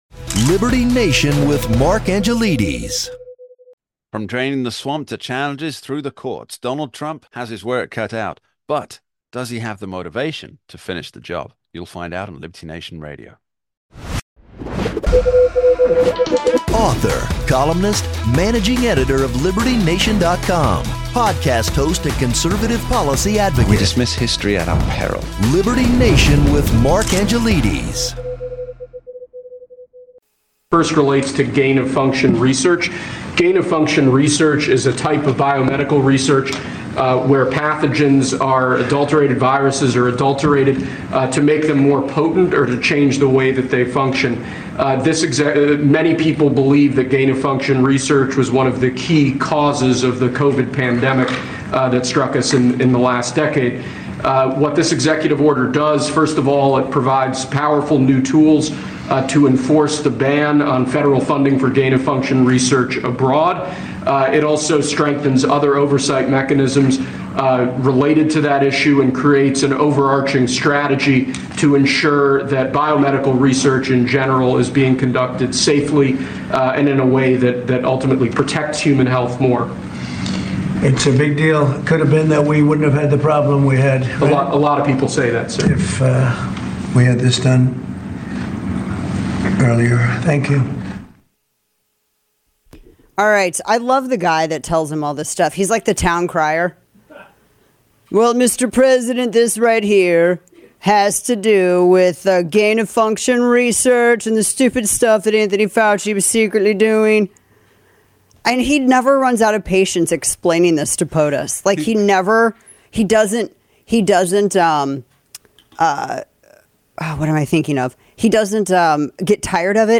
Â Rep. Beth Van Duyne joins us to break news about the letter the House GOP is sending to Speaker Johnson to DEMAND a $2T reduction in spending and to make the 2017 tax cuts permanent.